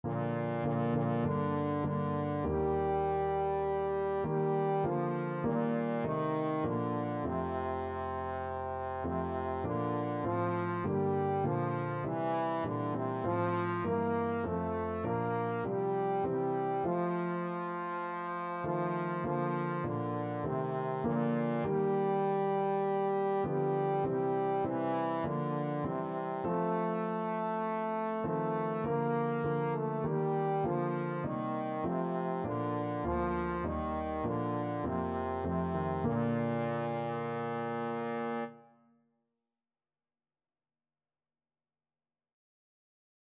Trombone
4/4 (View more 4/4 Music)
Bb major (Sounding Pitch) (View more Bb major Music for Trombone )
Classical (View more Classical Trombone Music)